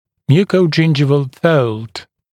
[ˌmjuːkəuˈdʒɪndʒɪvl fəuld][ˌмйу:коуˈджиндживл фоулд]переходная складка десны